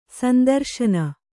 ♪ sandarśana